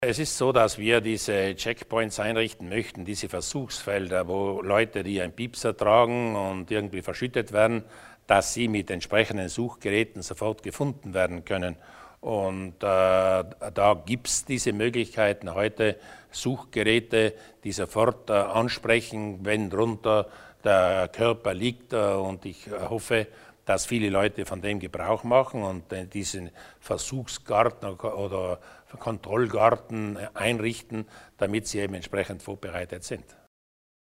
Landeshauptmann Durnwalder über neue Initiativen zur Sicherheit am Berg